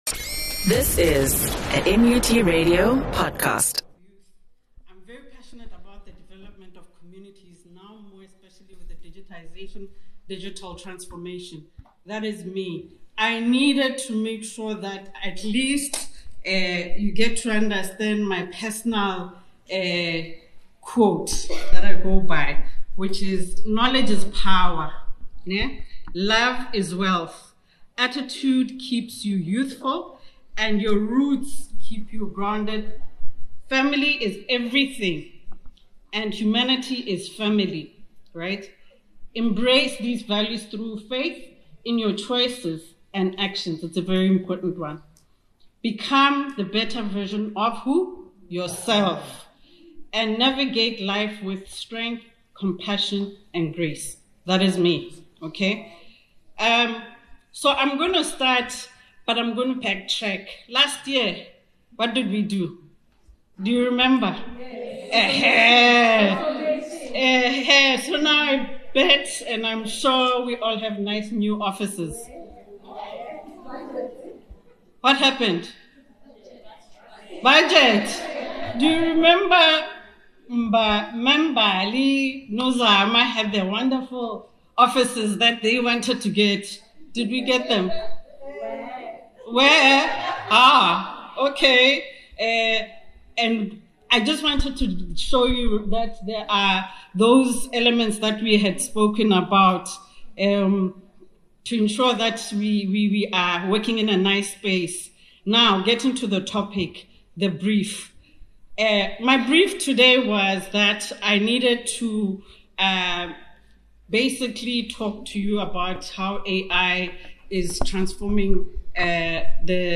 presentation on the EAC 2025